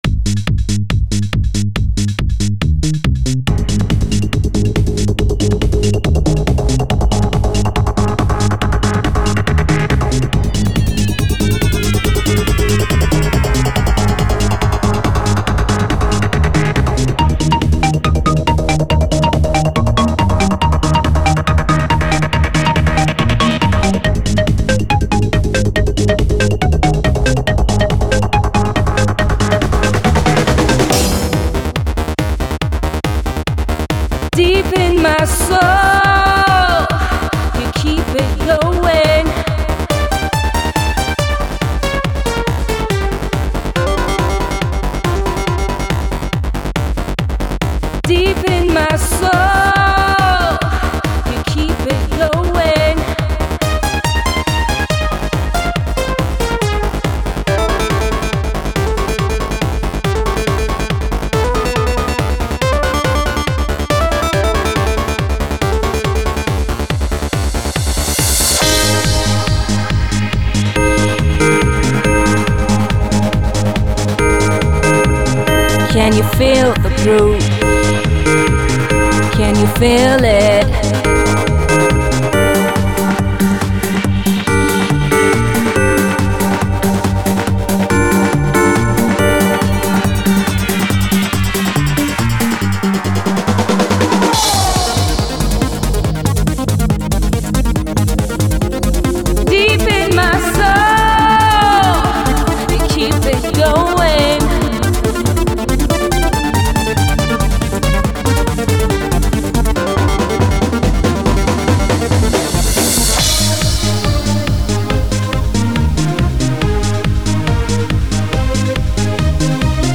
Techno χορευτική διάθεση